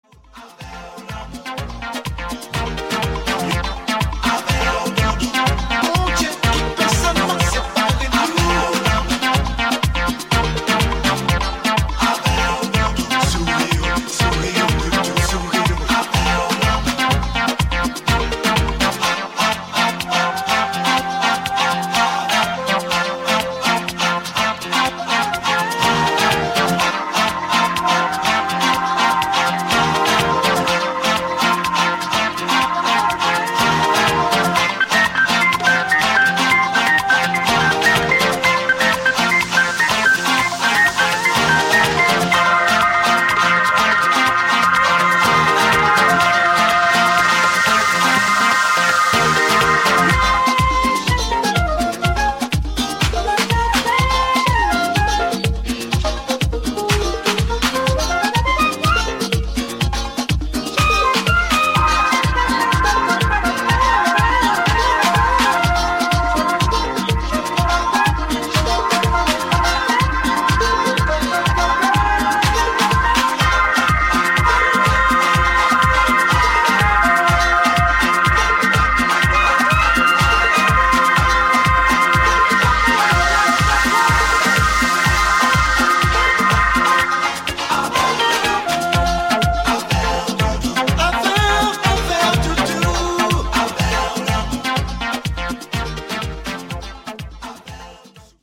お勧めのディスコティックな一枚です！！
ジャンル(スタイル) DISCO HOUSE